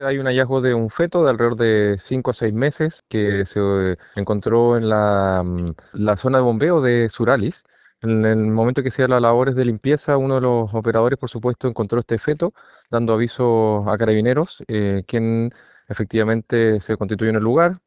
En conversación con el alcalde de la comuna, Javier Arismendi, explicó lo anterior y detalló que apenas fue hallado el feto, se solicitó la concurrencia de Carabineros.